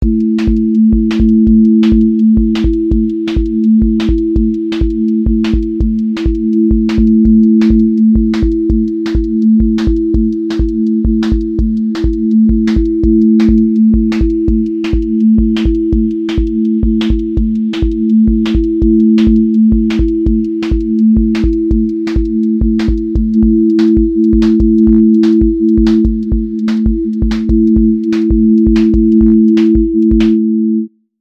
QA Listening Test drum-and-bass Template: dnb_break_pressure
• voice_kick_808
• texture_vinyl_hiss
Dusty lofi x drum-and-bass break pressure with rhodes haze, vinyl hiss, rolling atmosphere, and clean switchups